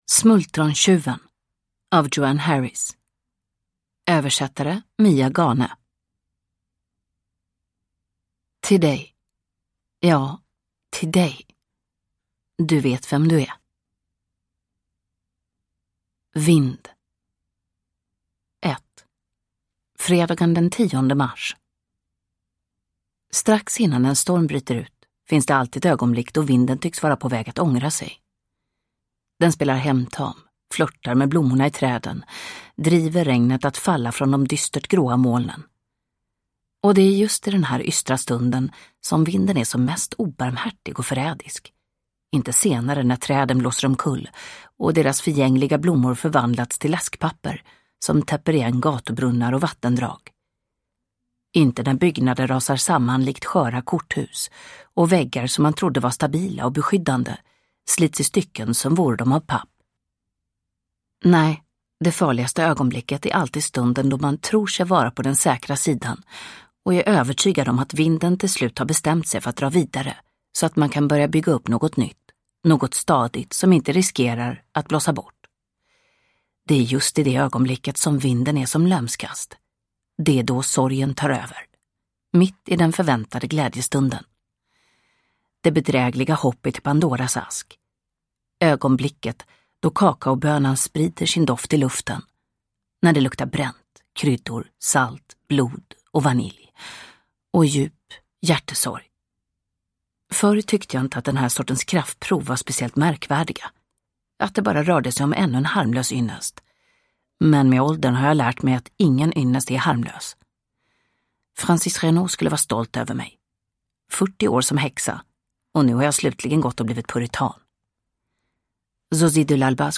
Smultrontjuven – Ljudbok – Laddas ner